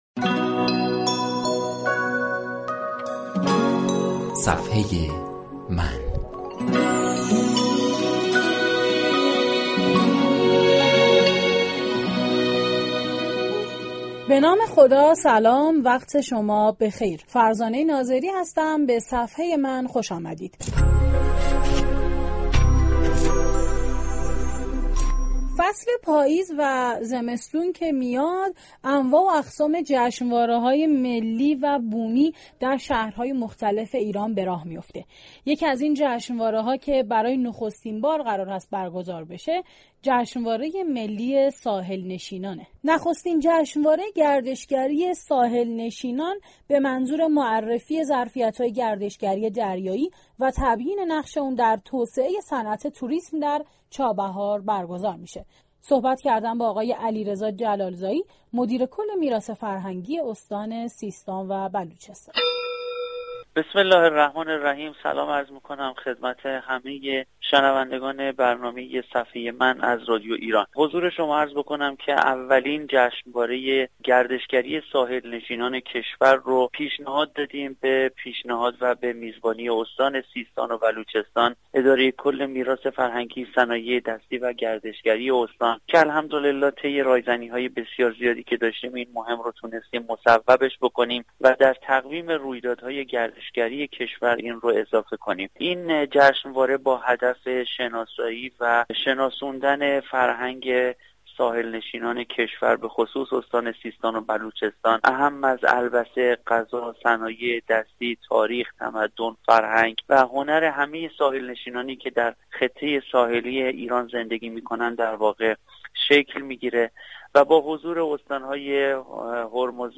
علیرضا جلال زایی مدیر كل میراث فرهنگی استان سیستان و بلوچستان در گفت و گو با «صفحه من» به جزئیات نخستین جشنواره گردشگری ساحل نشینان كشور پرداخت و در این باره گفت: این جشنواره با هدف شناساندن فرهنگ ساحل نشینان ایران اعم از لباس،غذا، تاریخ، فرهنگ و هنر به مردم و با حضور استان هرمزگان، سیستان و بلوچستان، خوزستان بوشهر، گیلان، مازندران، گلستان اسفند سال جاری به میزبانی شهرستان چابهار برگزار می شود.